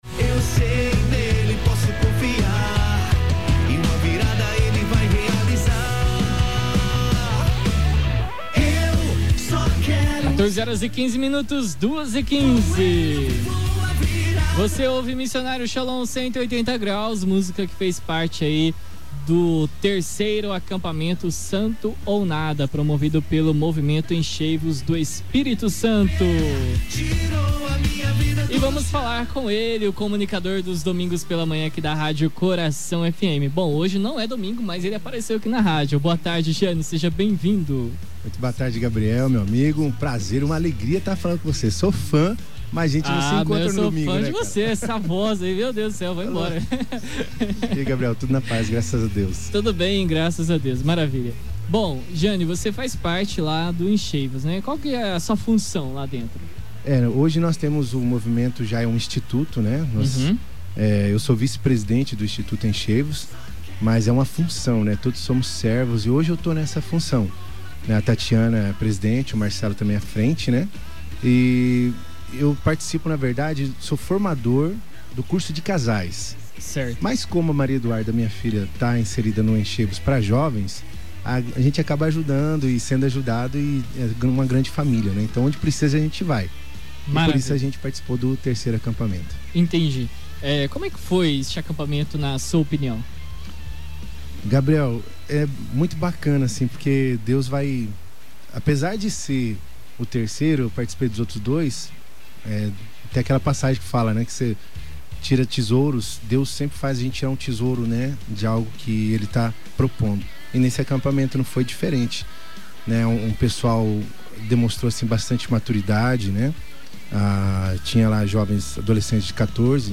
'Sintonia da Paz': testemunho dos participantes do 3º acampamento 'Santo ou nada'
Ouça na íntegra o testemunho das jovens: tercacptoencheivos.mp3 Envie seu Comentário